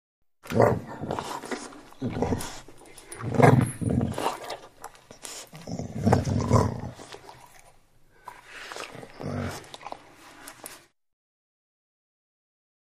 DogLabradorGrowl AT021901
Dog, Golden Labrador Growls, Jowl Flaps. Low Pitched, Subdued Growls With Jowl Smacks And Light Collar Tag Clinks. Close Perspective.